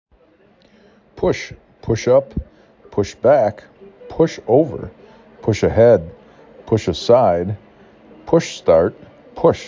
p u S